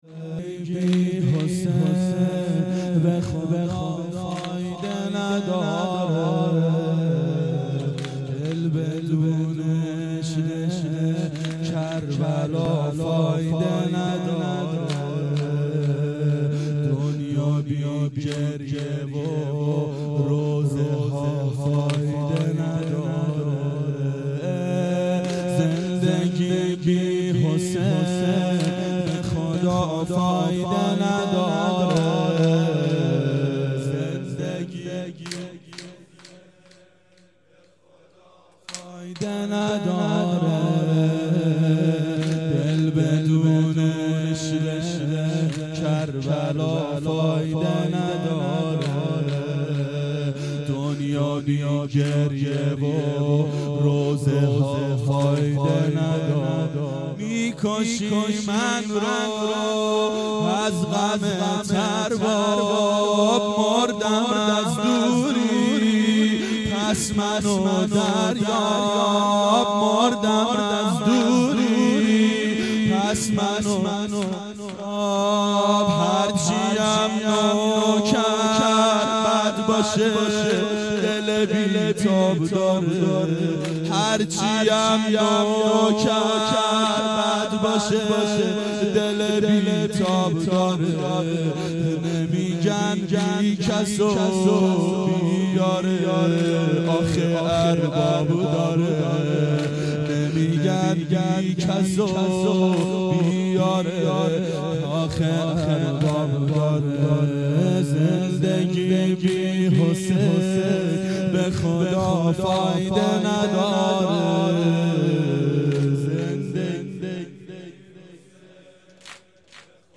• دهه اول صفر سال 1391 هیئت شیفتگان حضرت رقیه سلام الله علیها (شب شهادت)